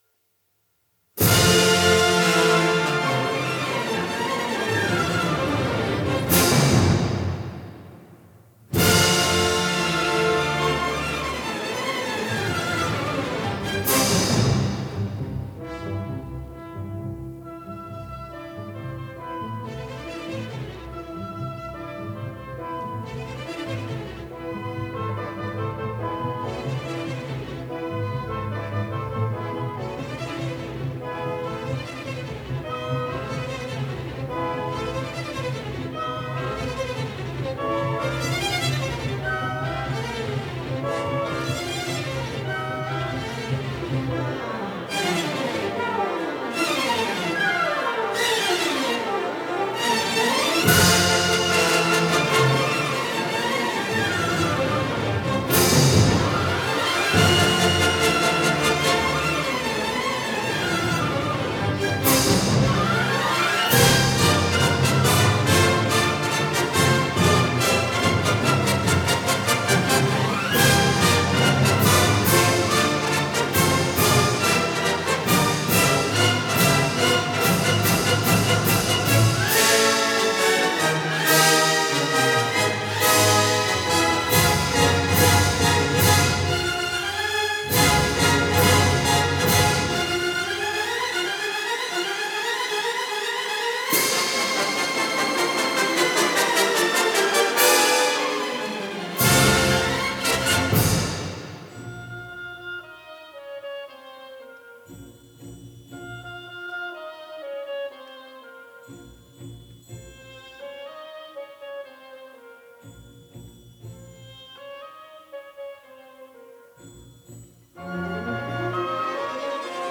Venue: St. George Hotel, Brooklyn, New York Producer